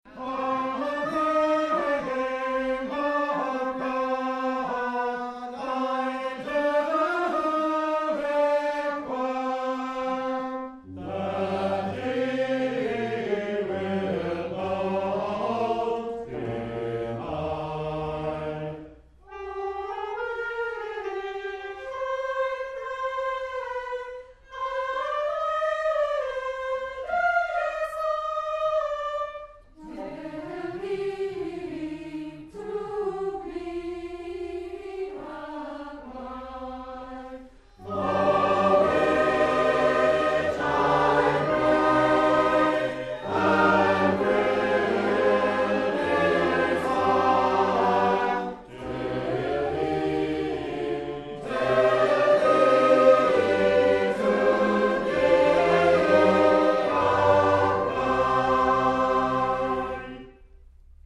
The volume contains 33 psalm settings and four anthems with the air in the tenor line 'as is usual in parish-church music'.
Selwich, with each vocal part singing a line of text before all parts join to sing the last two lines as a chorus, a style promoted by William Knapp some 40 years before.